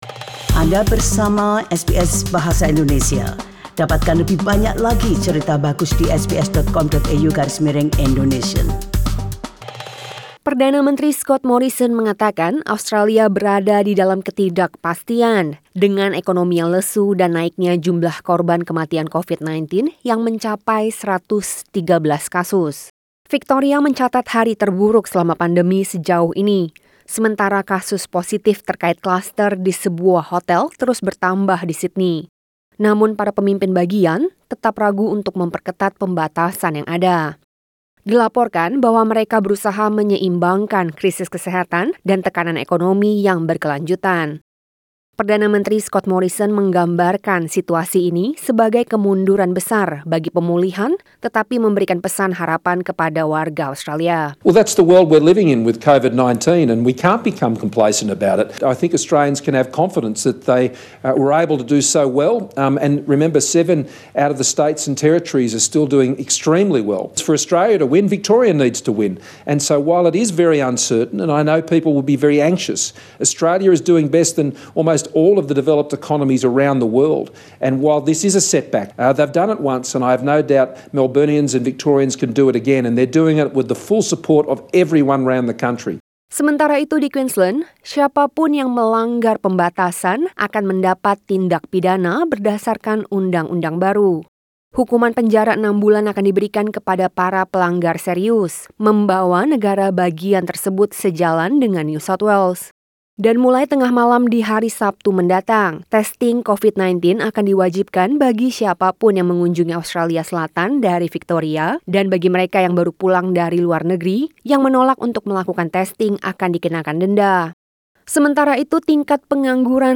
SBS Radio News in Bahasa Indonesia - 17 July 2020